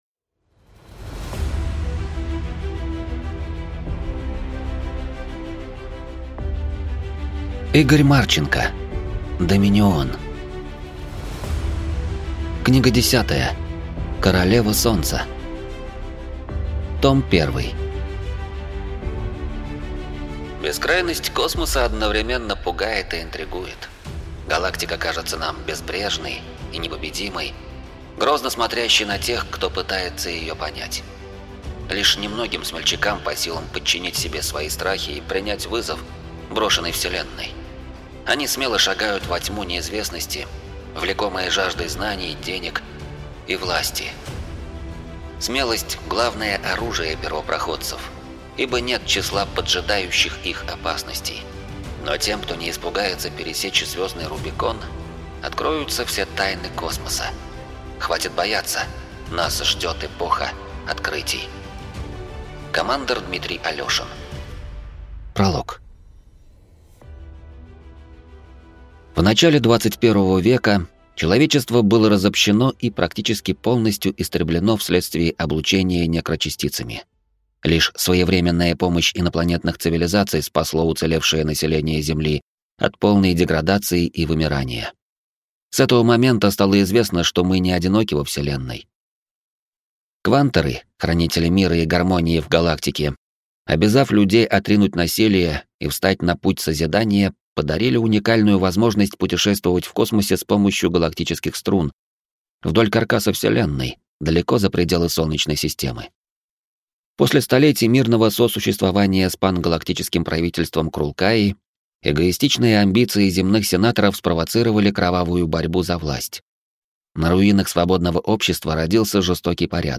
Аудиокнига Королева солнца. Том 1 | Библиотека аудиокниг